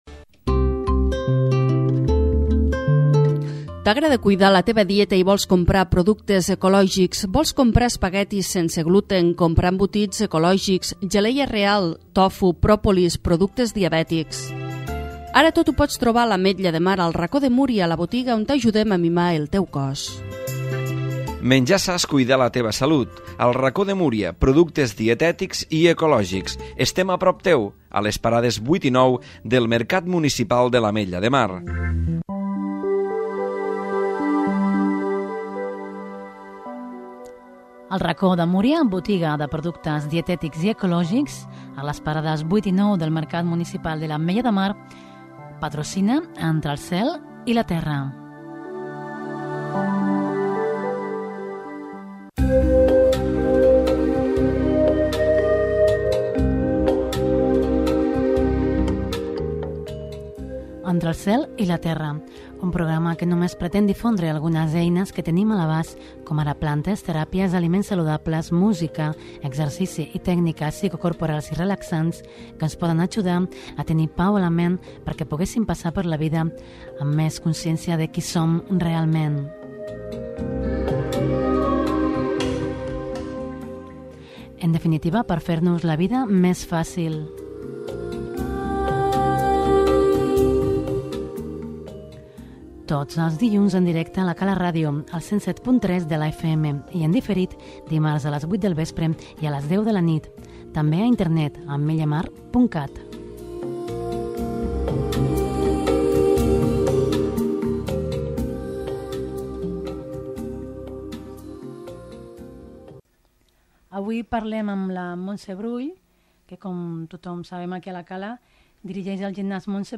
L'edició del 26 d'octubre d'ECT conté dues entrevistes.